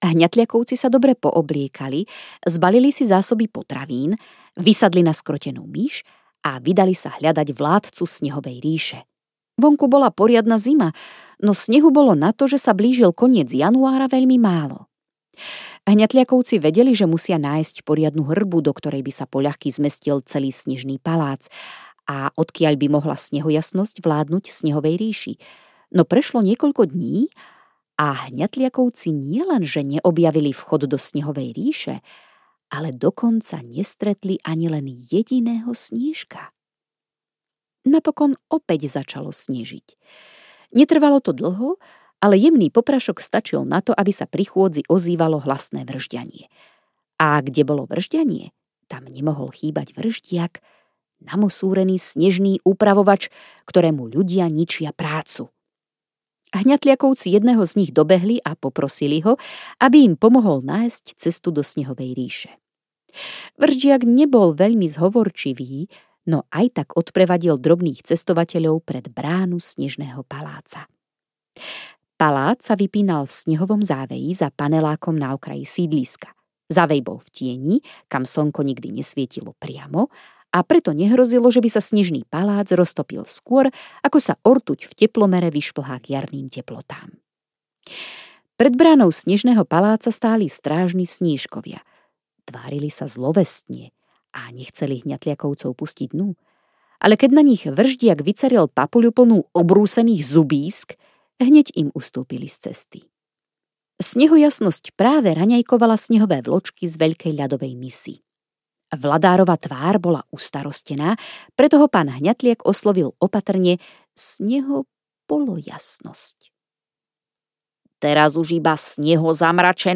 Najmenšia rodina na svete audiokniha
Ukázka z knihy